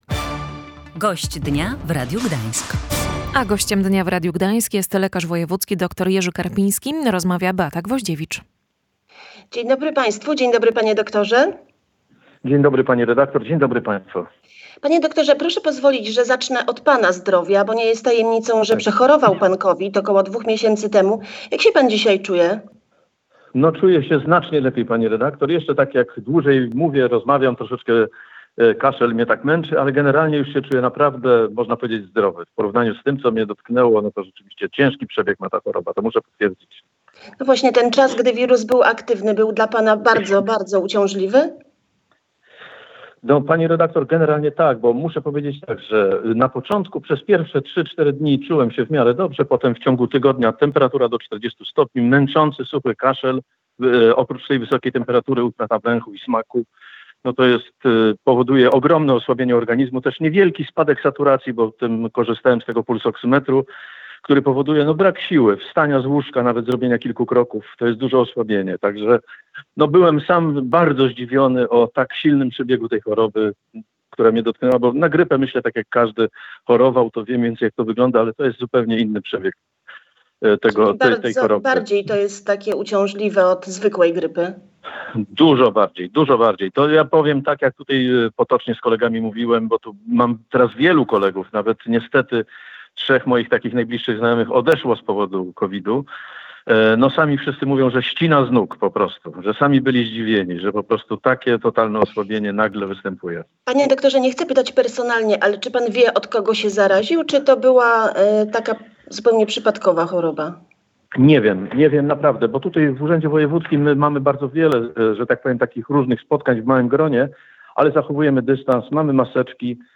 Jesteśmy już gotowi na jego otwarcie – mówił w Radiu Gdańsk lekarz wojewódzki Jerzy Karpiński.